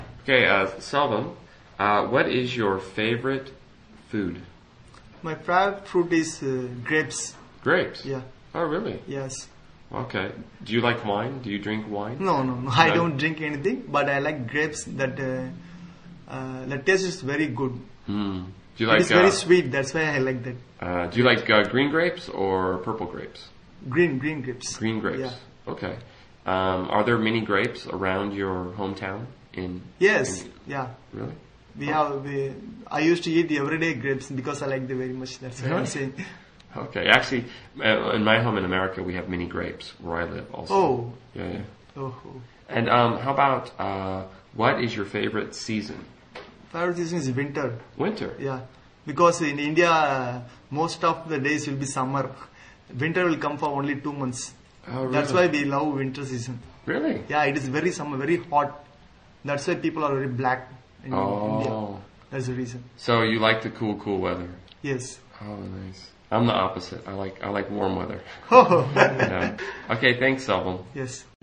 英语初级口语对话正常语速03：最喜爱的水果（MP3+lrc）